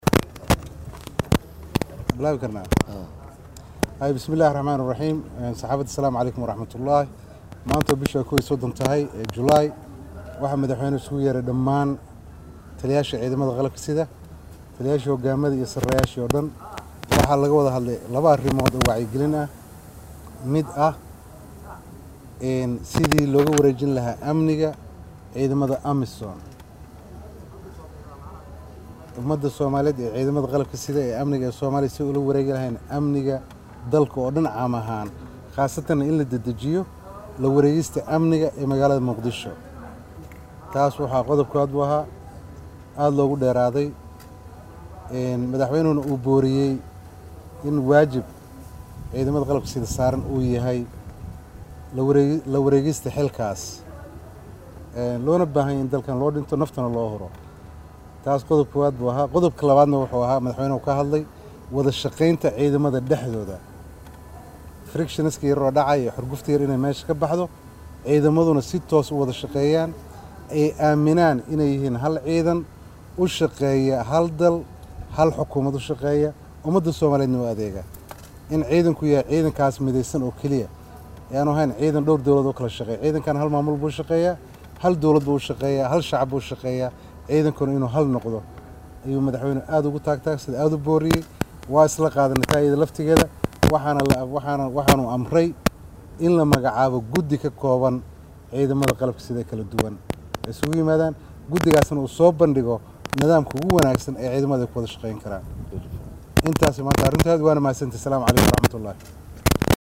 Jeneraal Bashiir Cabdi Maxamed oo ah Taliye ku xigeenka ciidanka Booliiska Soomaaaliyeed ayaa kulankaasi markii uu dhammaaday kadib saxaafadda u faah faahiyey waxyaabihii laga wada hadlay.